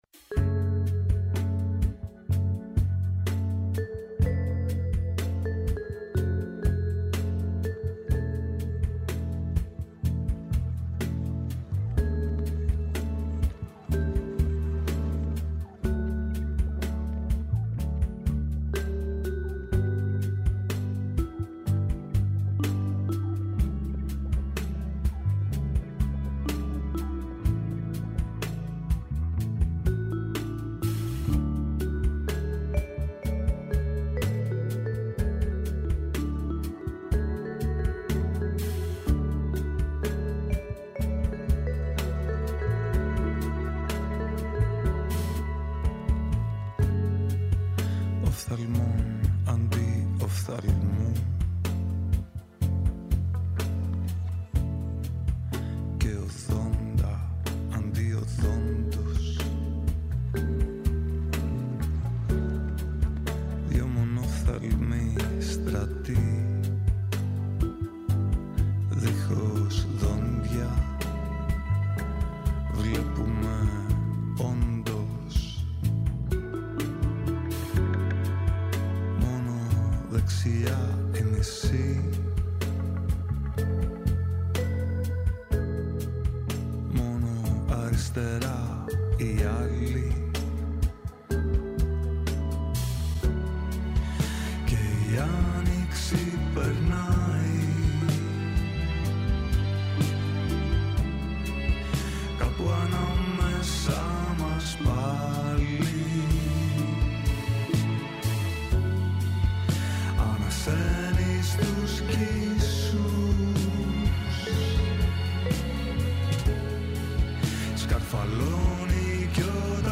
Καλεσμένοι απόψε εκτάκτως 18:00-20:00: